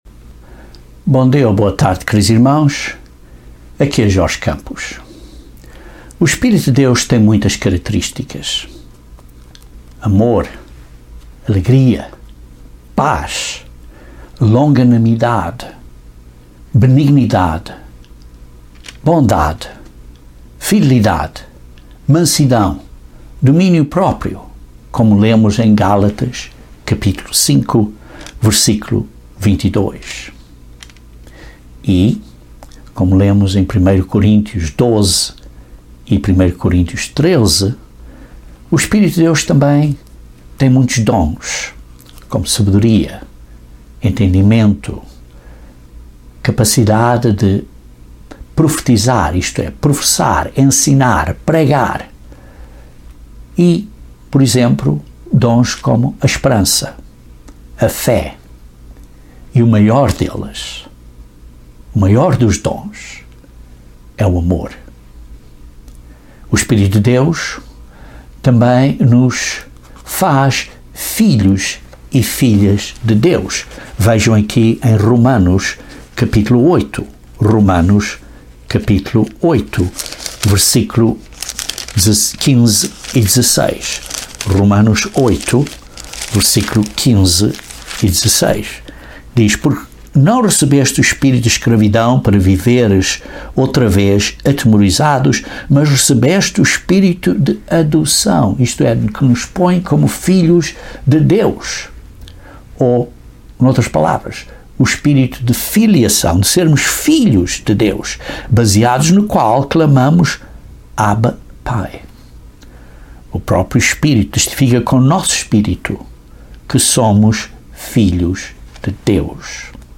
Uma dessas características é o poder de Deus (Atos 1:8). Este sermão foca nesta característica do Espírito Santo.